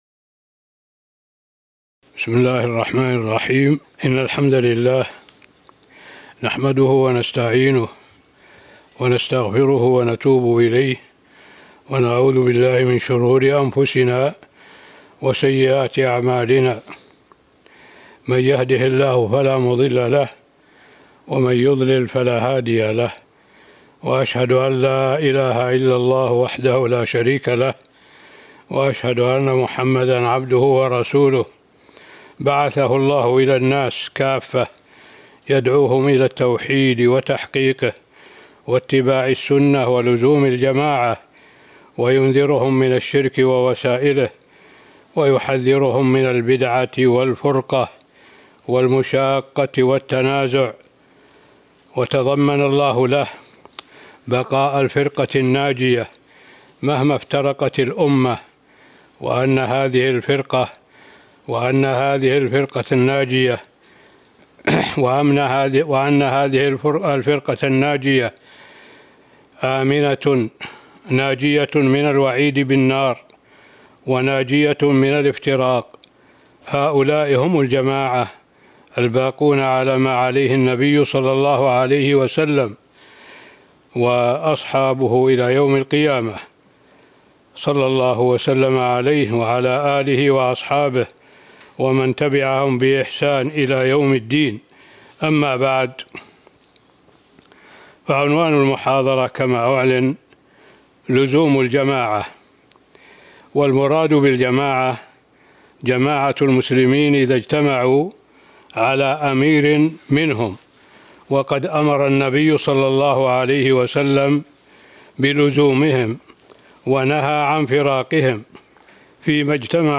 تاريخ النشر ١٠ صفر ١٤٤٢ هـ المكان: المسجد النبوي الشيخ: معالي الشيخ الدكتور صالح بن عبد الله العبود معالي الشيخ الدكتور صالح بن عبد الله العبود لزوم الجماعة